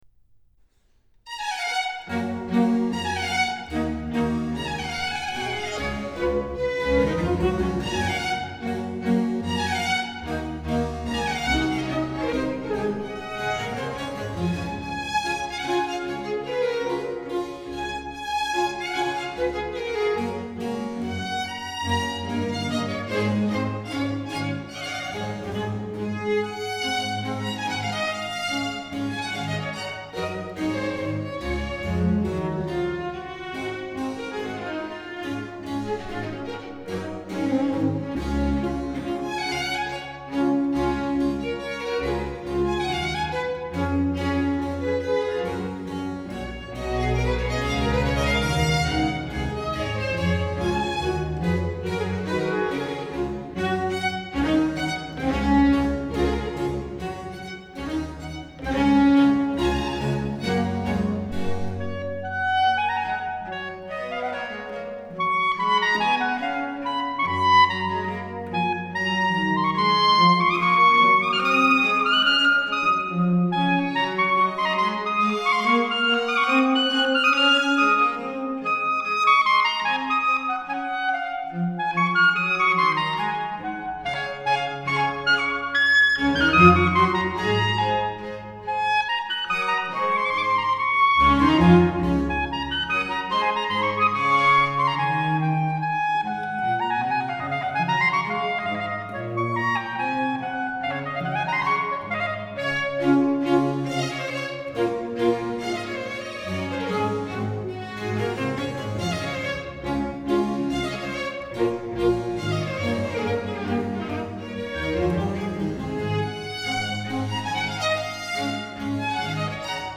Concerto for clarinet and small orchestra No.3 in G major